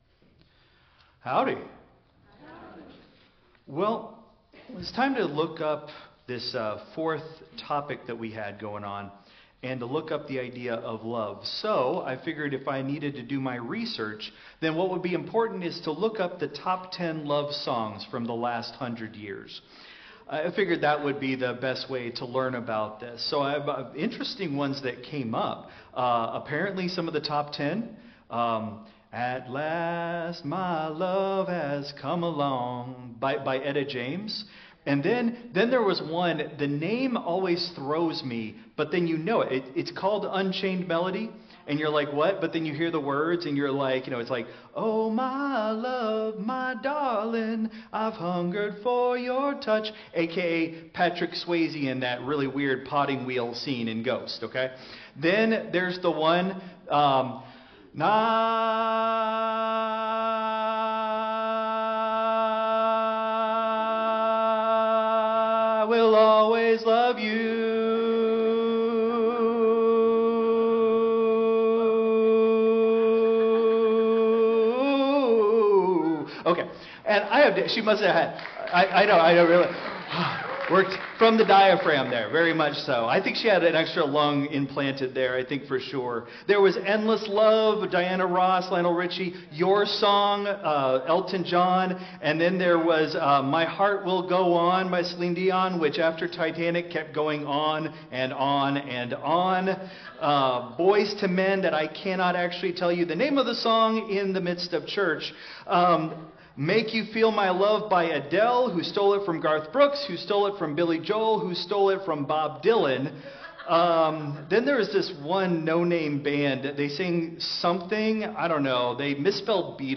Christ Memorial Lutheran Church - Houston TX - CMLC 2024-12-22 Sermon (Contemporary)